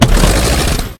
tank-engine-load-1.ogg